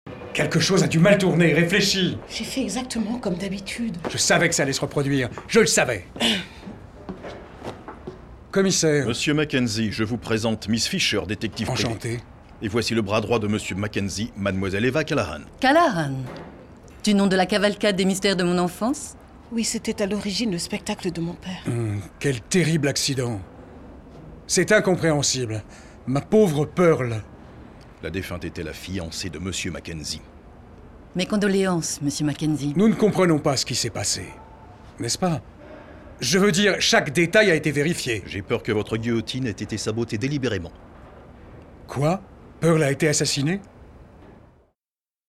VOIX DOUBLAGE – SERIE « Miss Fisher enquête » (commissaire Robinson)